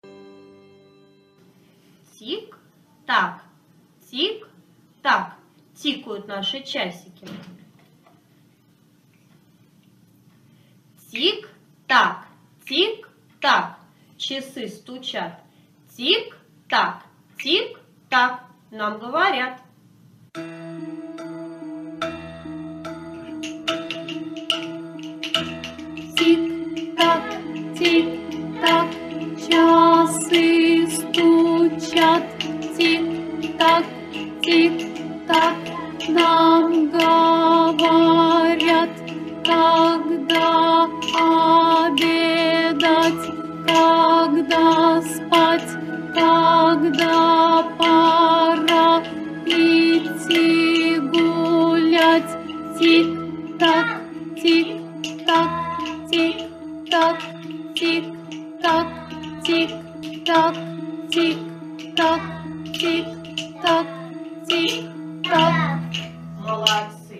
Распевка "Часики"